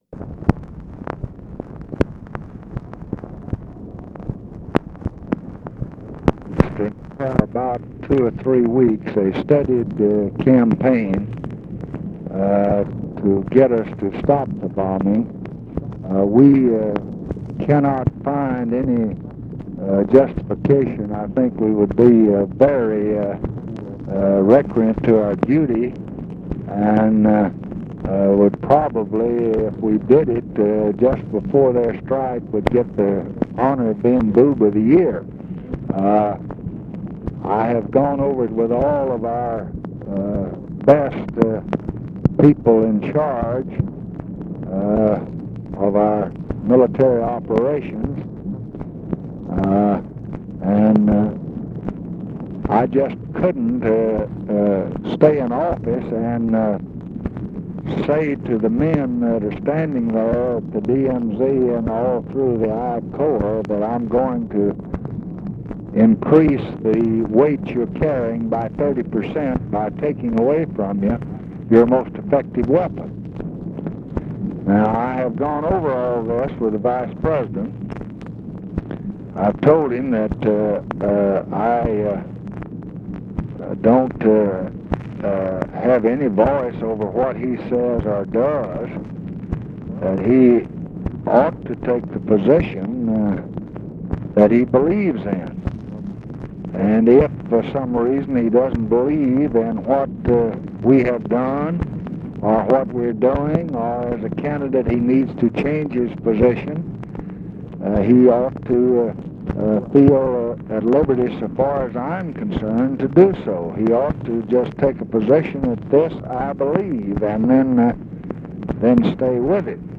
Conversation with RICHARD HUGHES, July 31, 1968
Secret White House Tapes